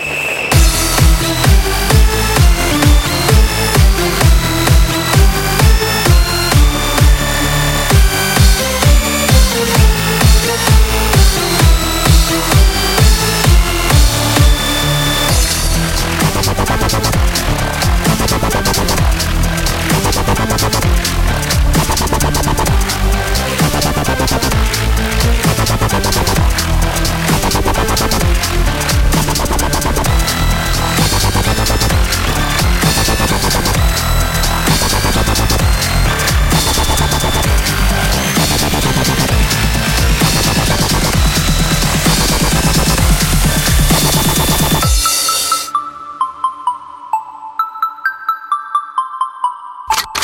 • Качество: 133, Stereo